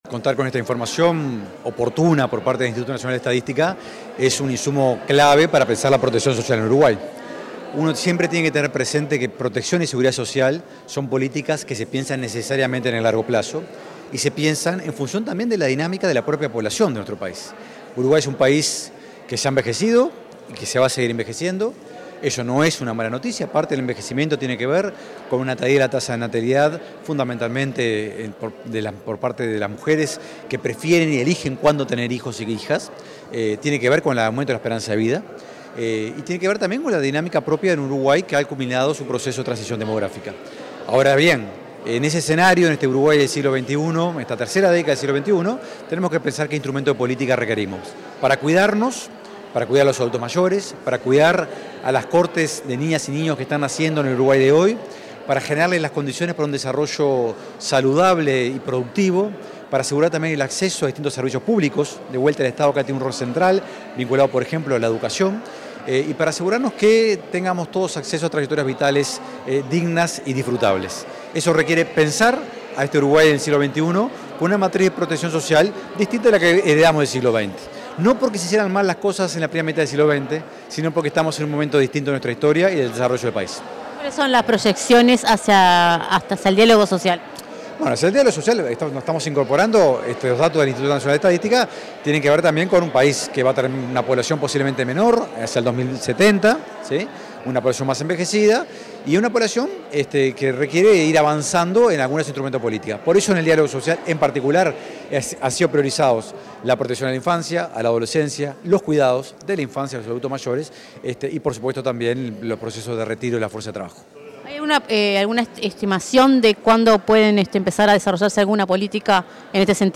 Declaraciones del director de OPP, Rodrigo Arim
El director de la Oficina de Planeamiento y Presupuesto (OPP), Rodrigo Arim, realizó declaraciones, en el marco de la presentación de las proyecciones